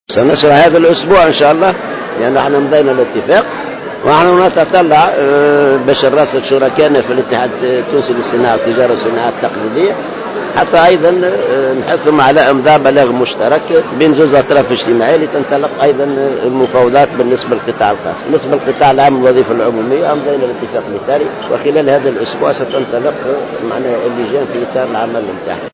وأضاف العباسي على هامش ندوة وطنية حول الاقتصاد الاجتماعي و التضامني أن الاتحاد سيحث منظمة الأعراف على إمضاء بلاغ مشترك بين الطرفين لتنطلق المفاوضات بخصوص القطاع الخاص.